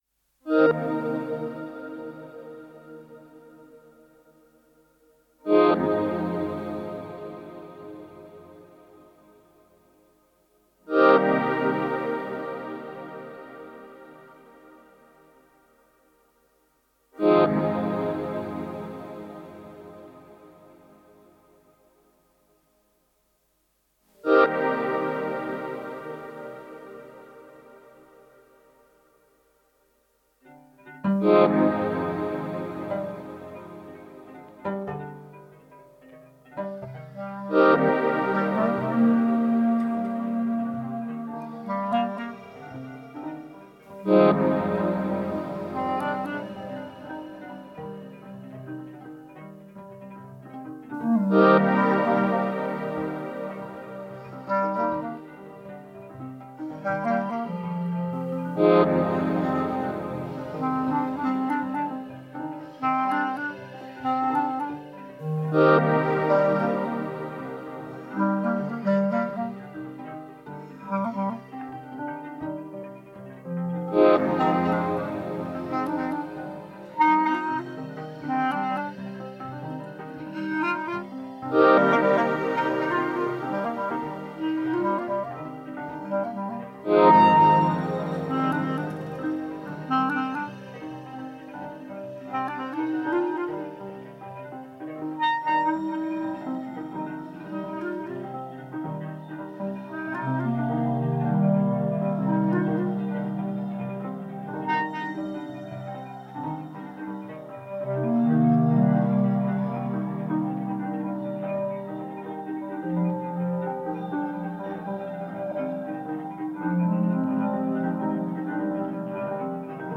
Alternative Ambient Electronic Experimental Synth Techno